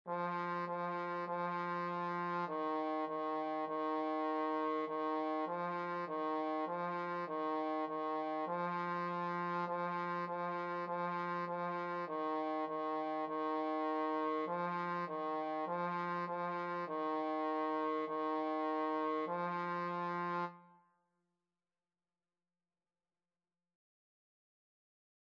2/4 (View more 2/4 Music)
Eb4-F4
Trombone  (View more Beginners Trombone Music)
Classical (View more Classical Trombone Music)